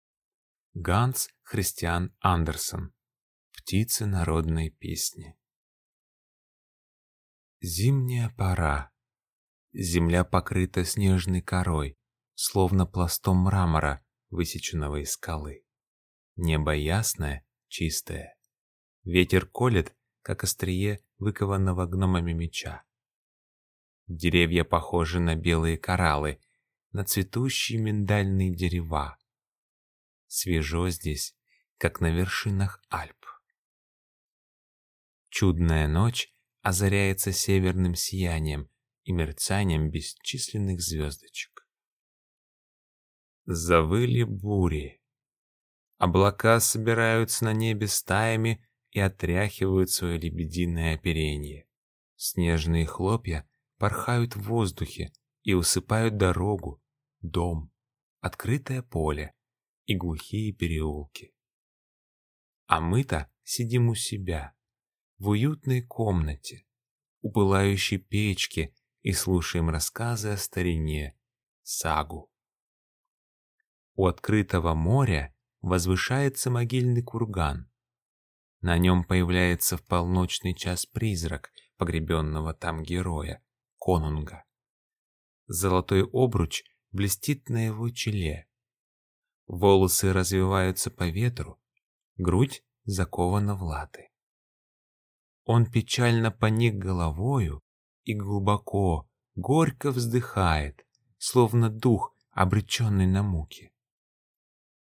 Аудиокнига Птица народной песни | Библиотека аудиокниг